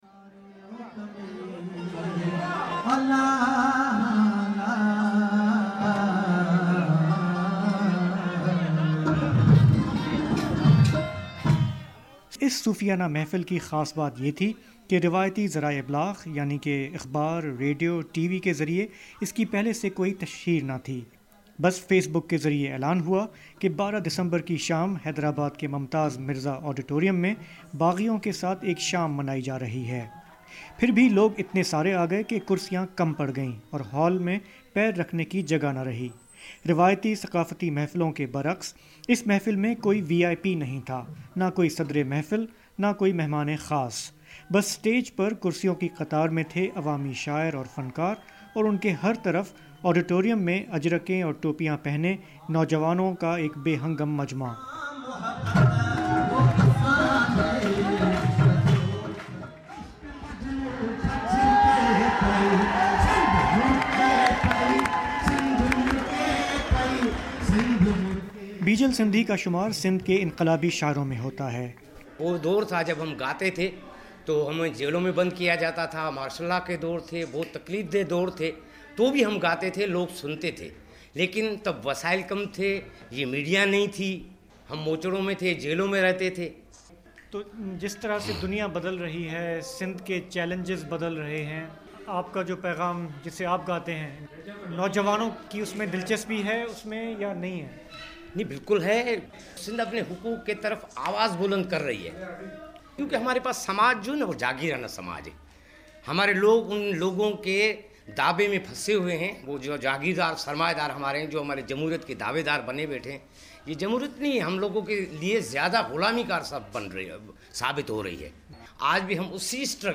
صوفیا کی دھرتی کہلانے والا صوبہ سندھ میں شاعر اور فنکار آج بھی قصبوں دیہاتوں میں اپنے فن کے ذریعے امن اور دھرتی سے پیار کا پیغام پھیلاتے ہیں۔ حیدرآباد میں صوفیوں کی ایک محفل میں کچھ مزاحمتی اور انقلابی شاعروں سے بات چیت